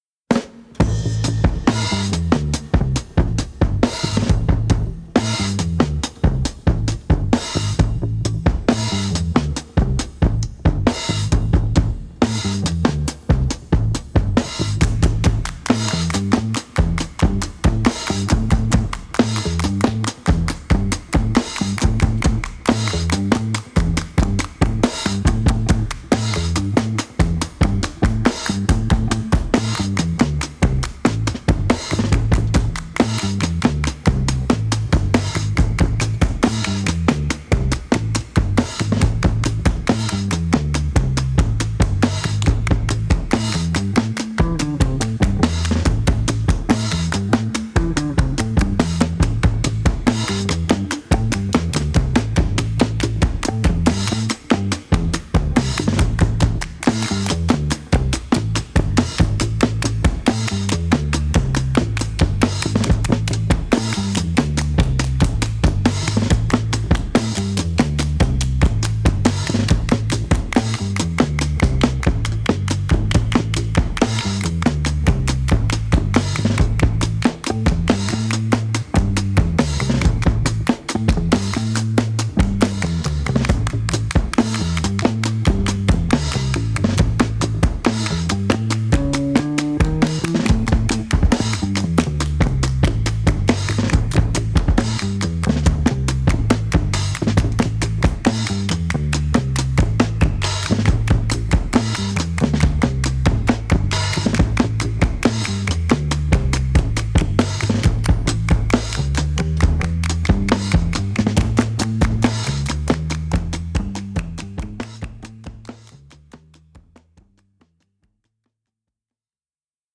an enthralling and totally infectious beat.